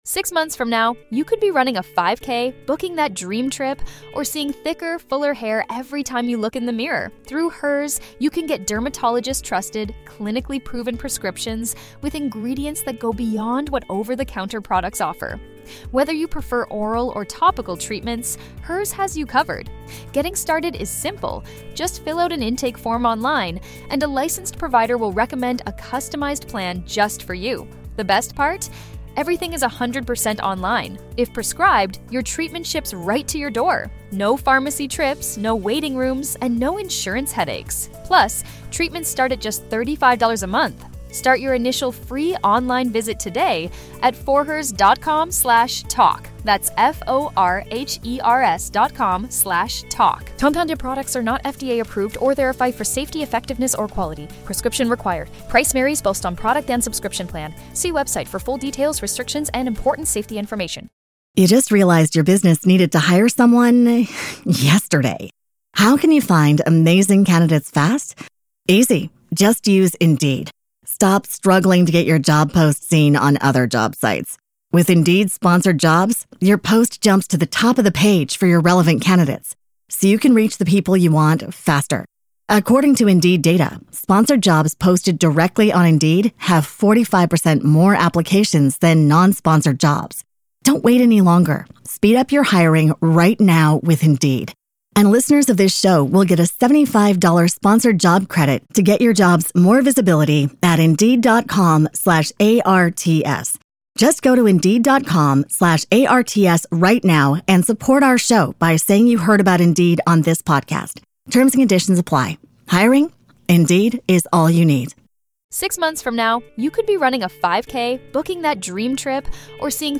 🎙FSC952 - Incontro con l’ospite d’onore Gary Jones (Deepcon 23, 19.3.2023).